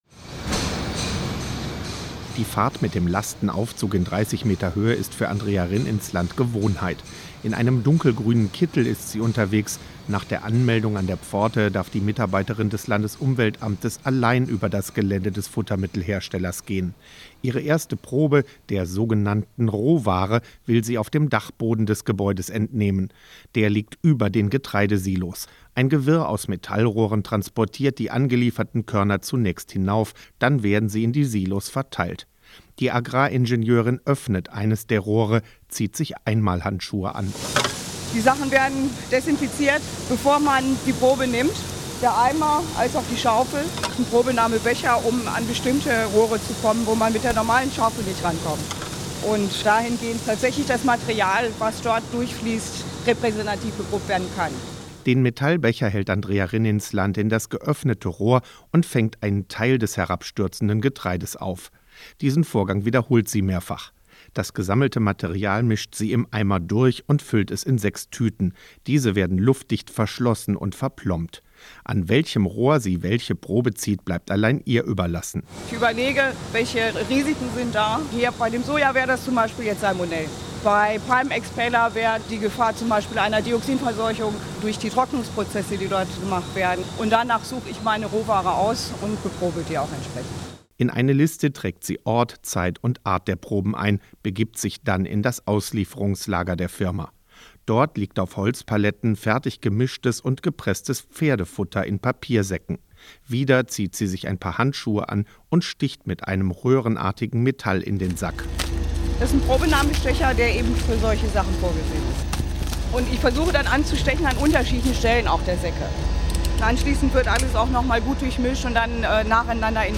Im Porträt einer Futtermittelkontrolleurin für das WDR 5 – Morgenecho wird deutlich, wie wenig Möglichkeiten die Behörden haben, um das Problem in den Griff zu bekommen.